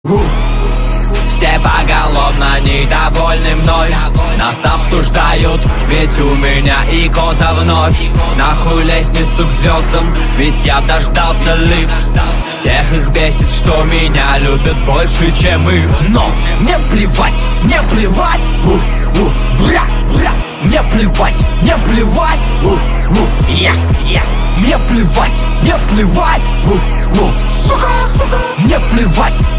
• Качество: 128, Stereo
русский рэп
злые
агрессивные